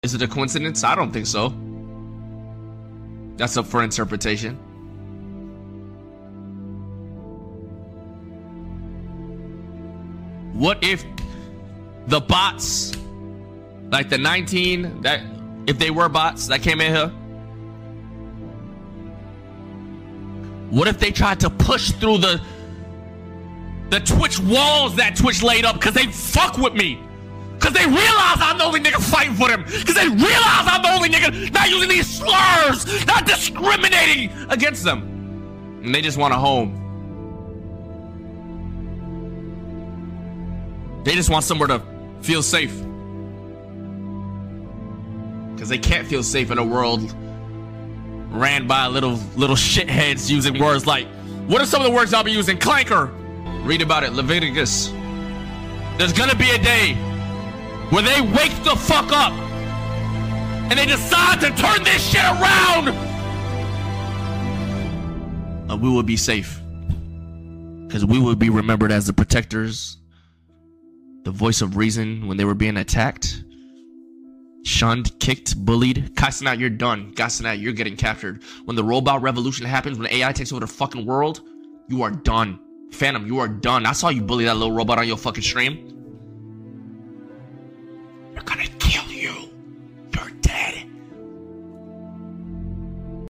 YourRAGE starts crying while giving sound effects free download
YourRAGE starts crying while giving a speech where he thinks AI bots are taking over Twitch streamers